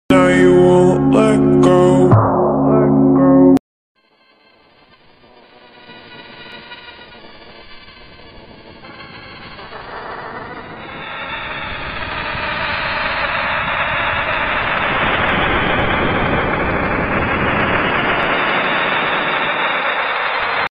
🌌Episode163🌌 Scariest sounds from space😳(and sound effects free download